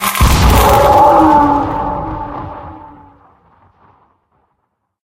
48d440e14c Divergent / mods / Soundscape Overhaul / gamedata / sounds / monsters / poltergeist / death_1.ogg 31 KiB (Stored with Git LFS) Raw History
death_1.ogg